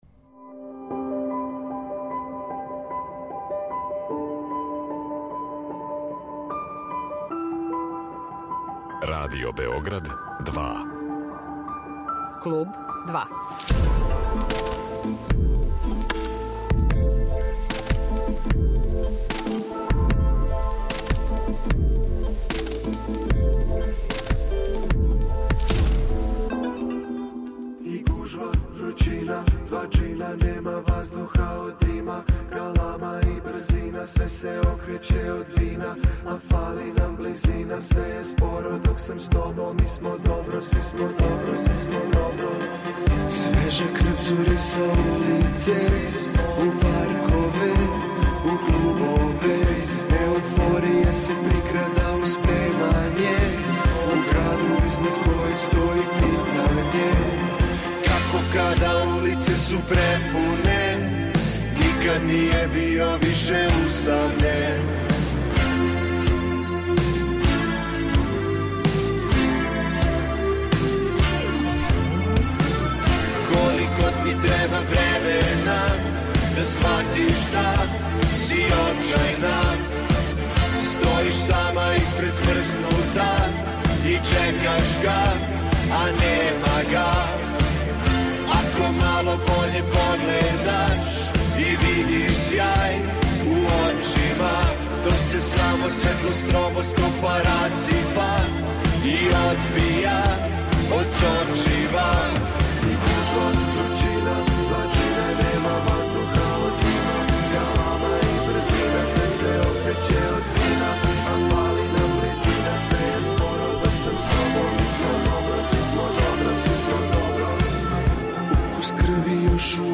Уз разговор о филму ћемо такође слушати управо ове песме.